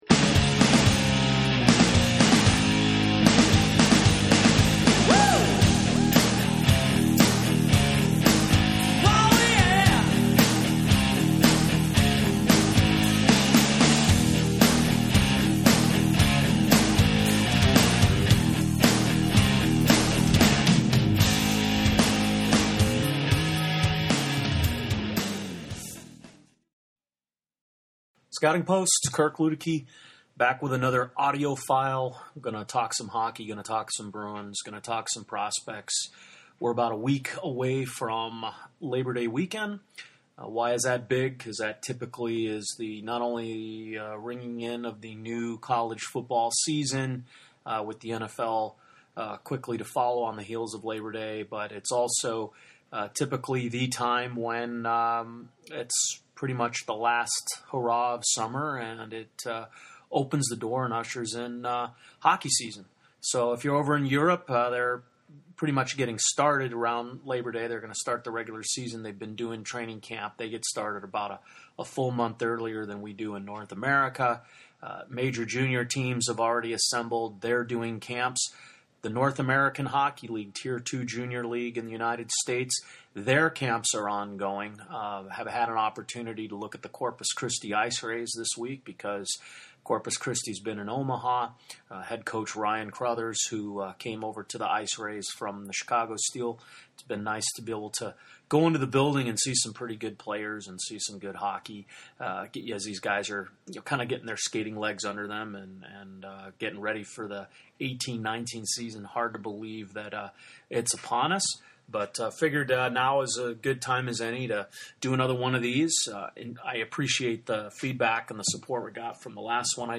Hey all- back with another audio cast file (Eric Carmen voice) all by myself…